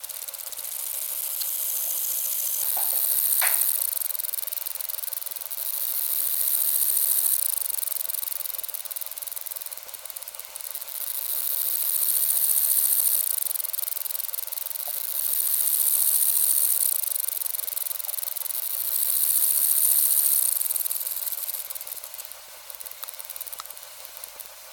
Laboraudioaufnahme. September 1973, 22°C. Uher 4000 Report mit Uher M53, Kasettennr. CS_U07G, 500 Hz High Pass Filter.